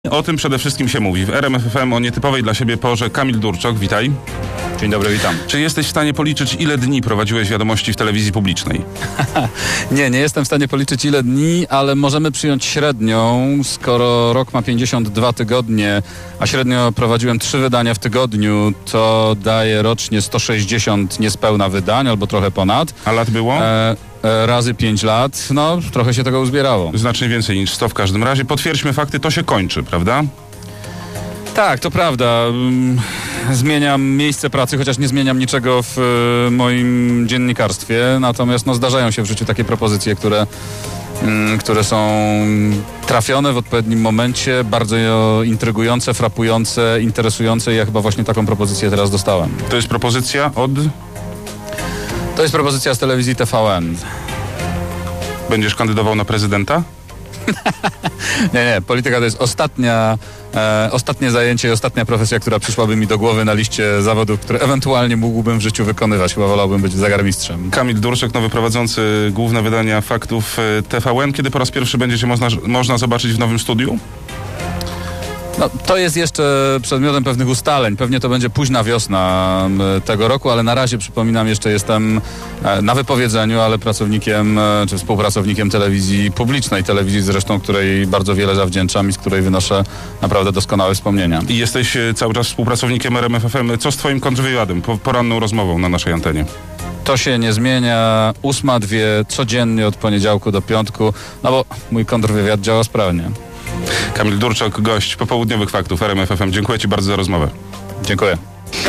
Posłuchaj nagrania wypowiedzi Kamila Durczoka dla RMF FM